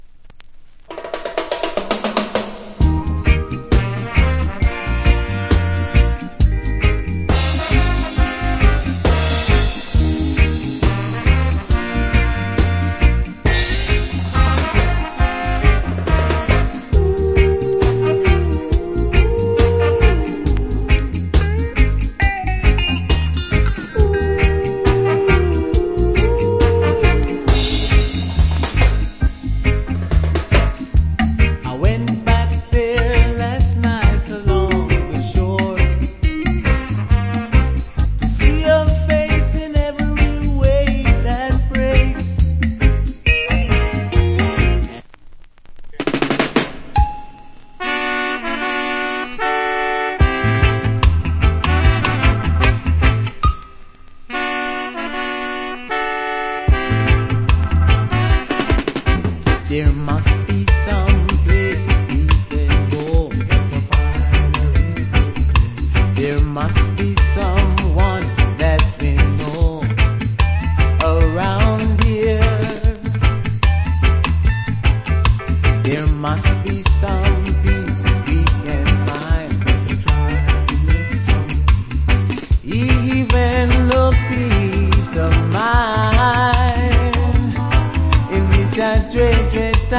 Cool Reggae Vocal.